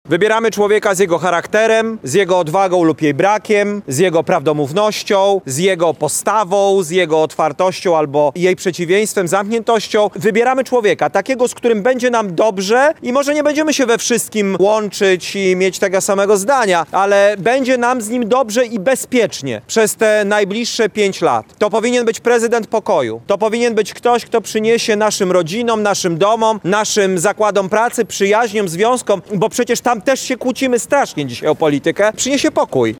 Kandydat na prezydenta Polski Szymon Hołownia gości w naszym regionie. Szef Polski 2050 spotkał się już z mieszkańcami Nałęczowa, a o 17.00 rozpoczął spotkanie na placu Litewskim w Lublinie.